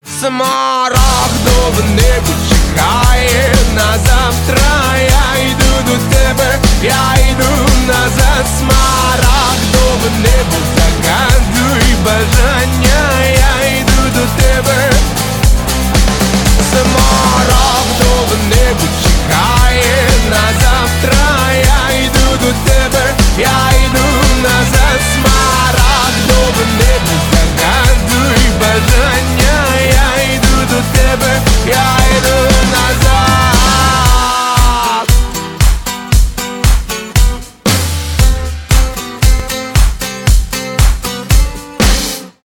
pop rock
альтернатива rock